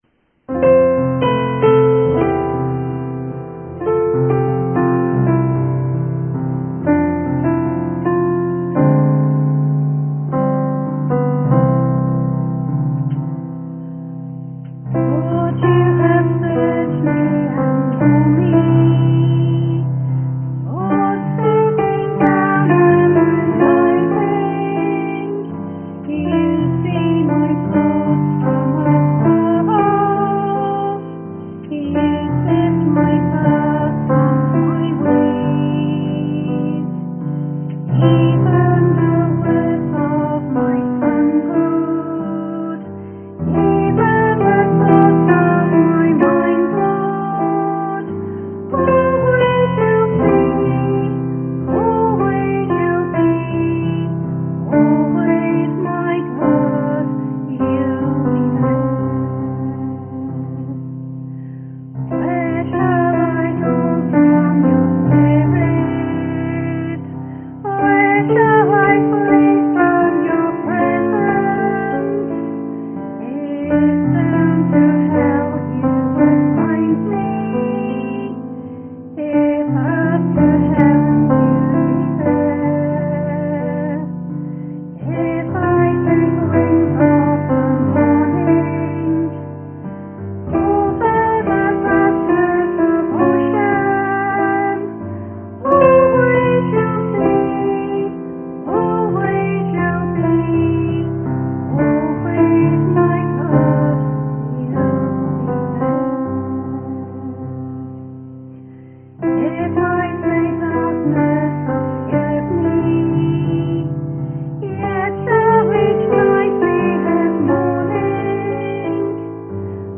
2025-06-01 (Pentecost AM)
Special Music
sung Wales UK 1 Jun 2025